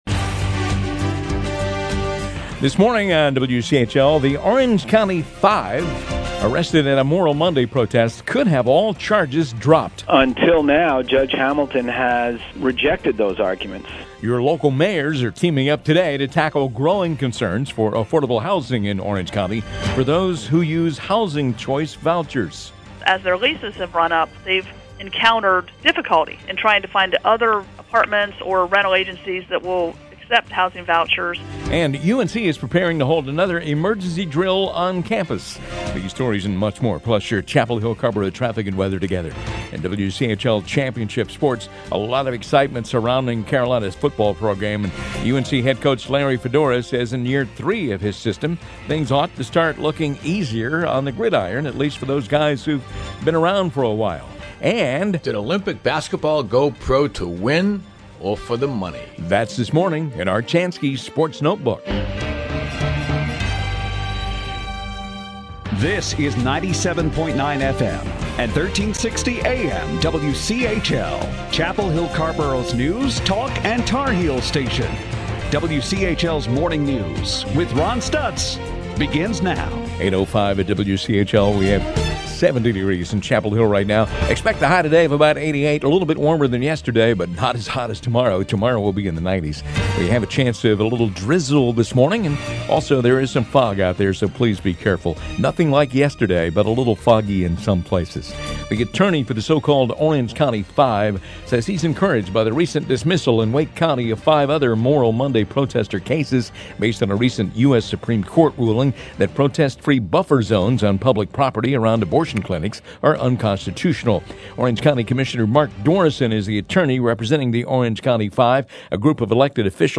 WCHL MORNING NEWS HOUR 3.mp3